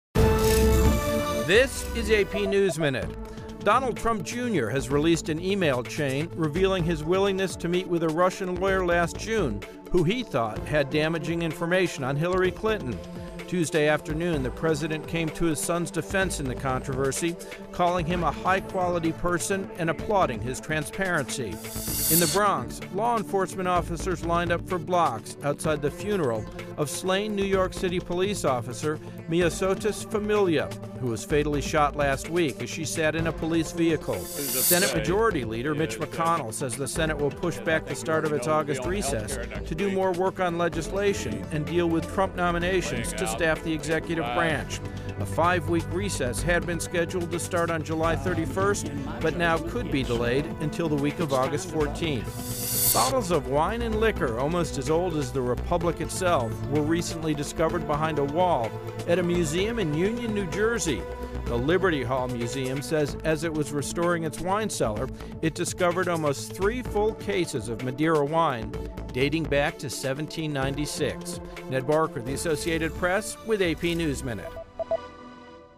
美联社新闻一分钟 AP 特朗普长子承认见俄罗斯律师 听力文件下载—在线英语听力室